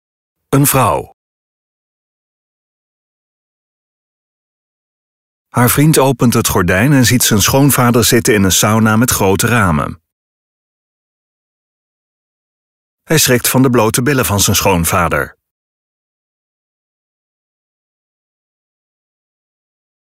(Een vrouw:) EEN ALARM
OPGEWEKTE MUZIEK Schat...